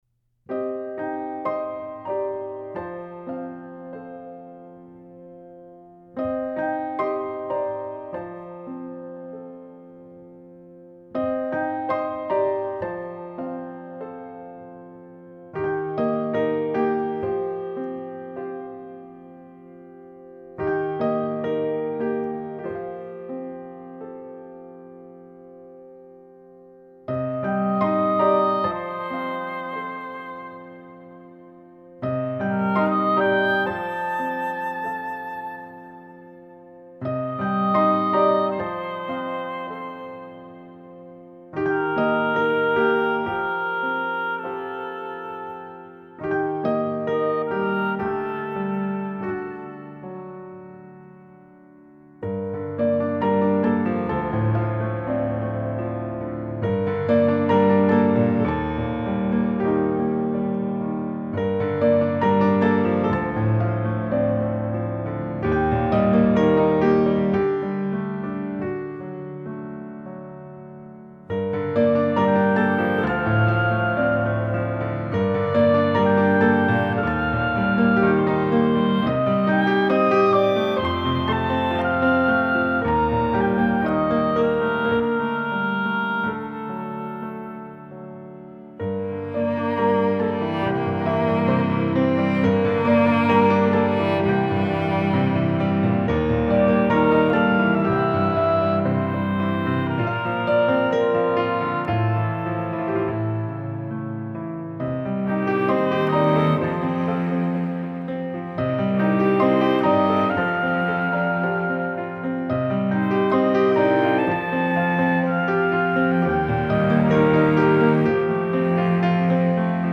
نوع آهنگ: لایت]